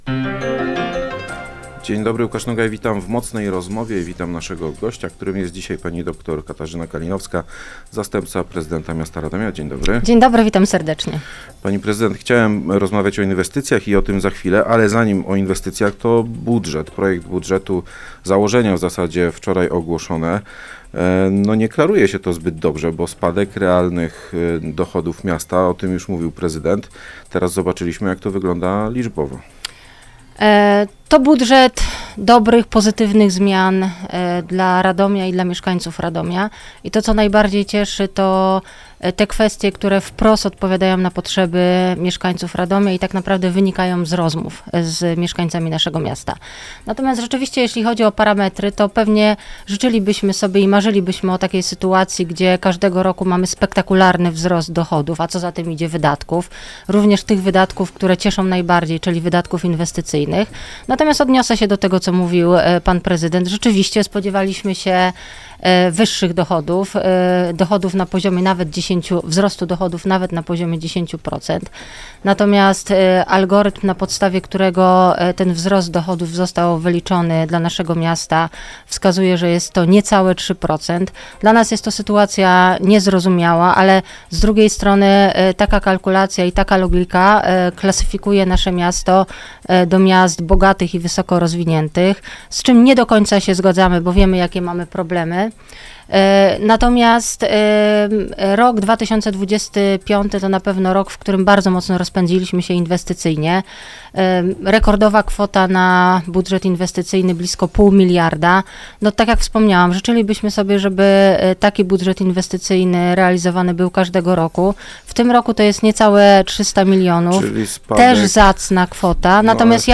Wiceprezydent Radomia Katarzyna Kalinowska